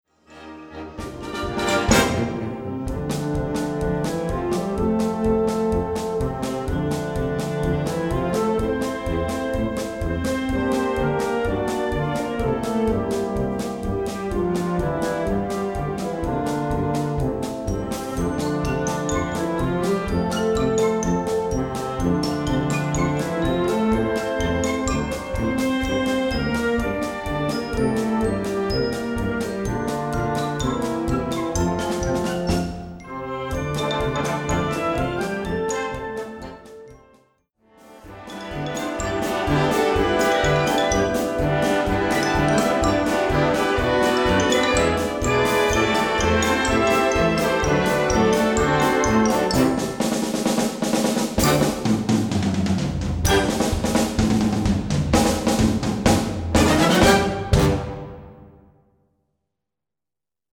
Gattung: Zirkusmusik
Besetzung: Blasorchester